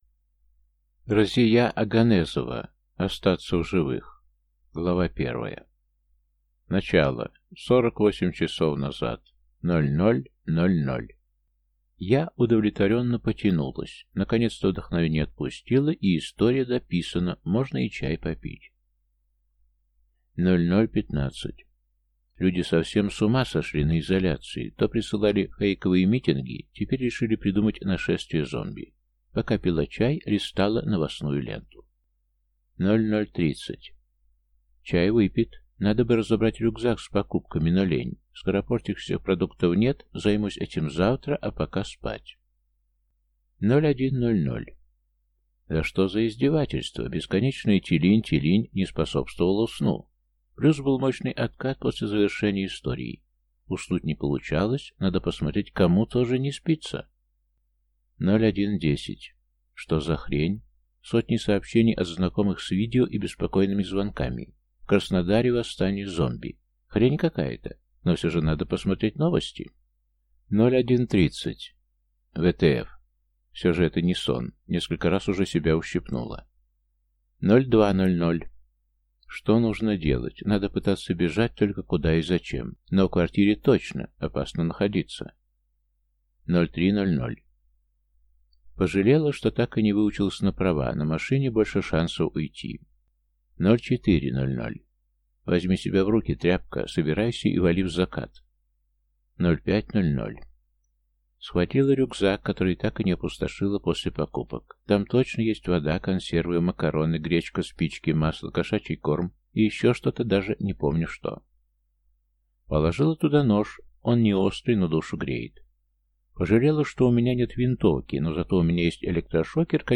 Аудиокнига Остаться в живых | Библиотека аудиокниг